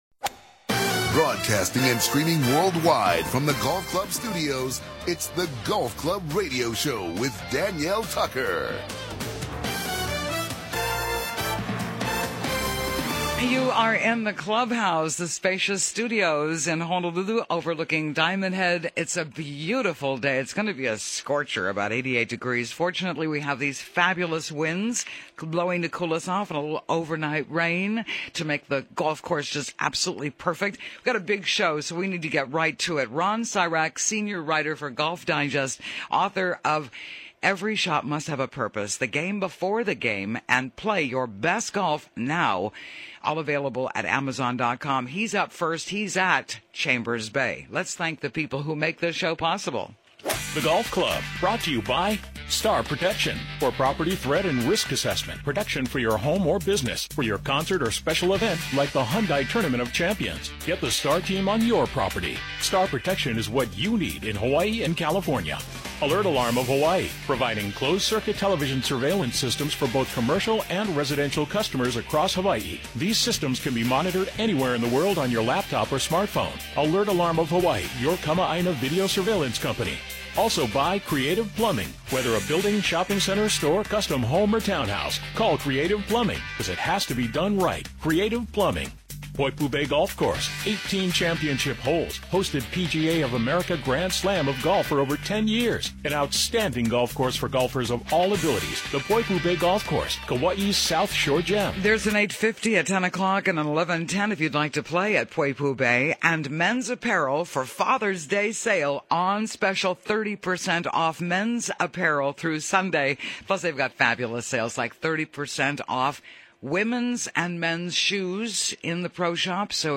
The US Open Chambers Bay Golf Club University Place, WA In the Clubhouse